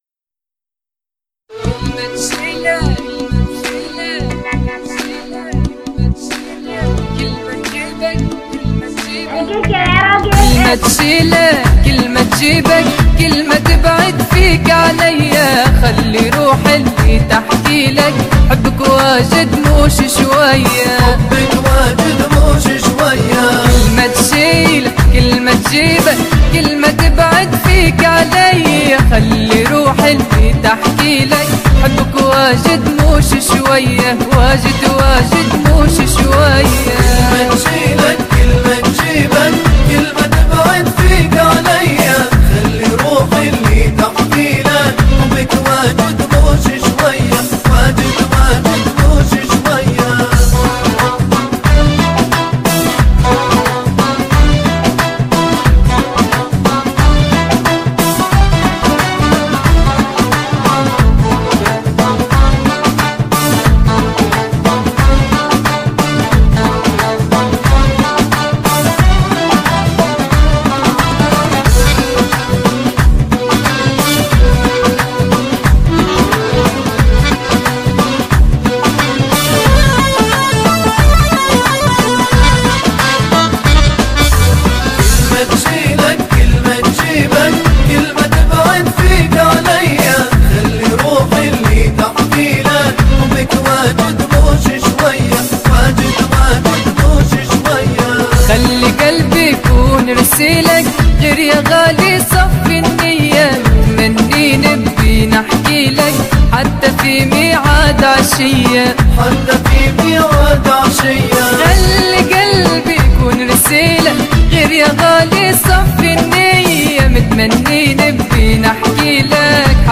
حصريا اغاني ليبيه